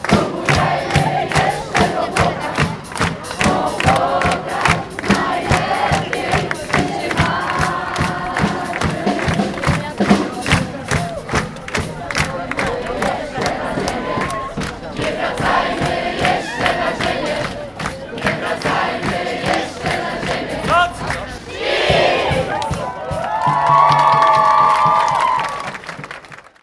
Na żnińskim Rynku kurator oświaty, policjanci, włodarze miasta i uczestnicy wspólnie zatańczyli belgijkę oraz odśpiewali hymn Przystanku PaT, którym jest utwór „Nie wracajmy jeszcze na ziemię” Jarosława Jara Chojnackiego.